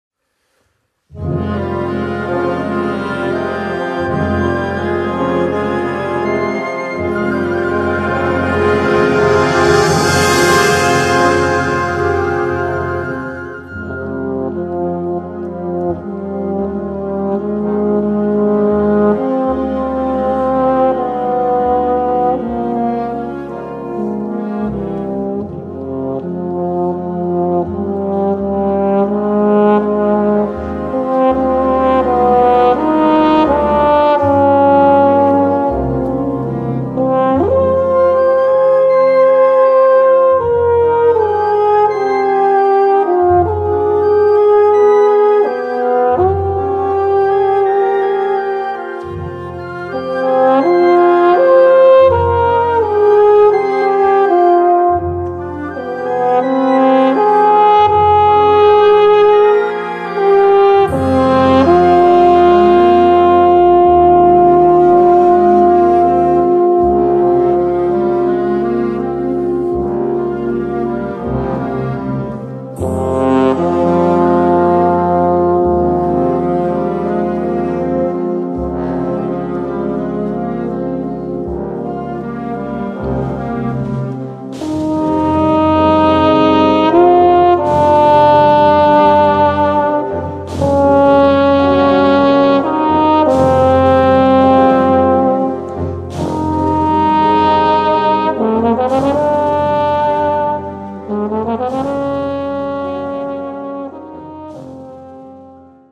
Voicing: Solo / Ensemble w/ Band